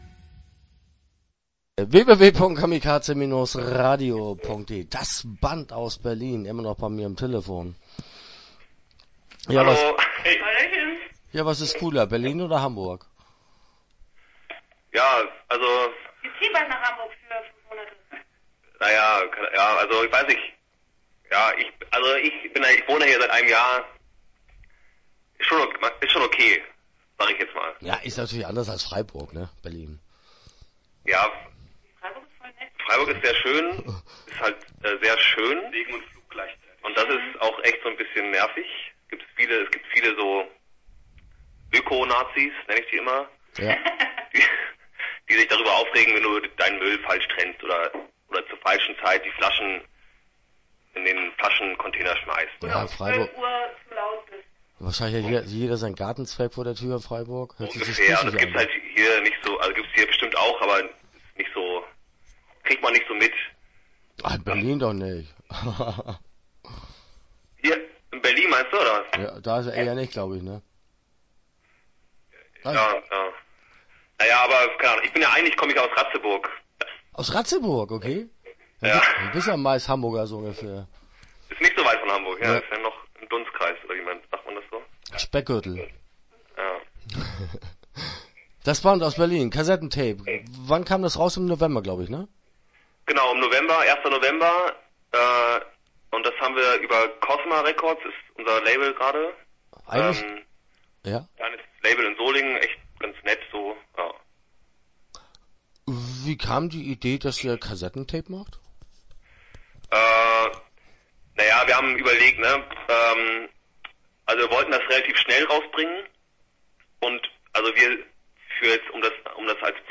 Das Band - Interview Teil 1 (9:55)